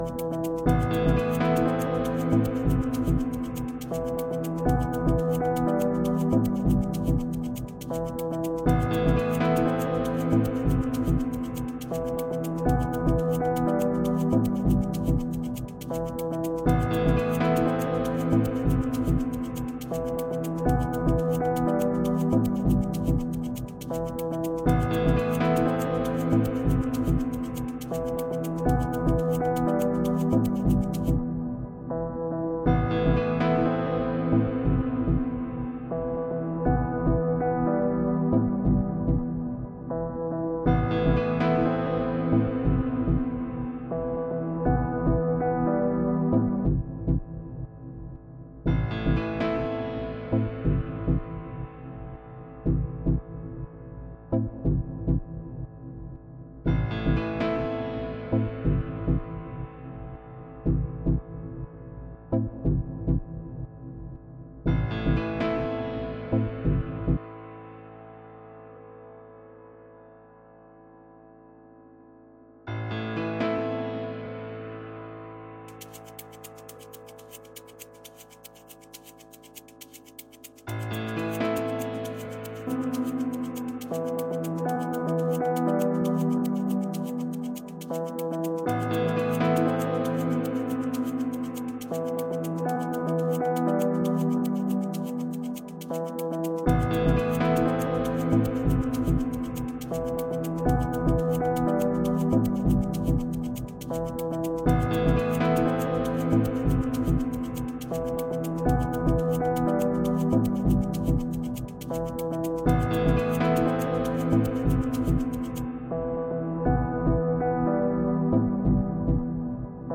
Žánr: Electro/Dance
Elektronika se snoubí s klasickou hudbou